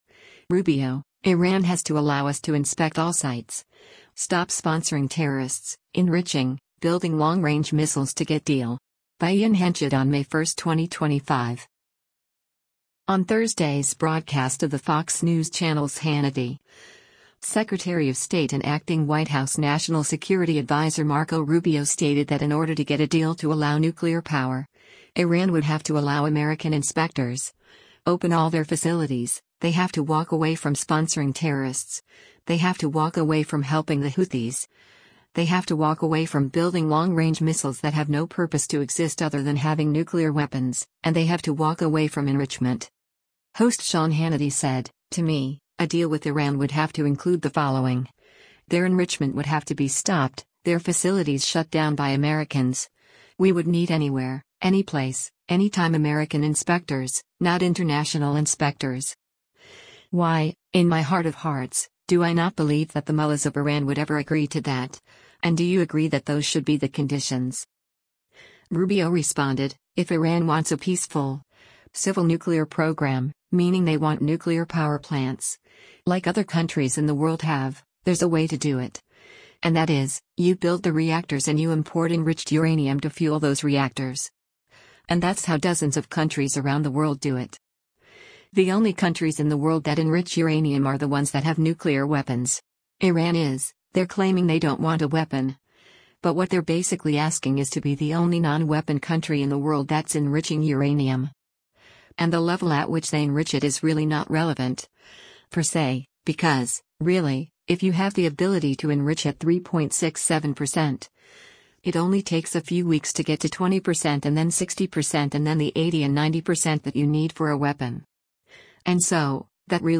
On Thursday’s broadcast of the Fox News Channel’s “Hannity,” Secretary of State and acting White House National Security Adviser Marco Rubio stated that in order to get a deal to allow nuclear power, Iran would have to allow American inspectors, open all their facilities, “they have to walk away from sponsoring terrorists, they have to walk away from helping the Houthis, they have to walk away from building long-range missiles that have no purpose to exist other than having nuclear weapons, and they have to walk away from enrichment.”